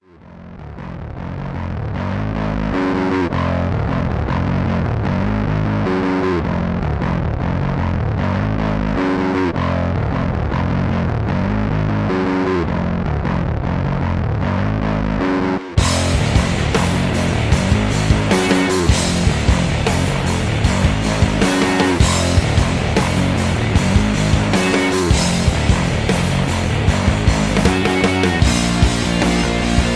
Tags: rap , r and b , rock , sound tracks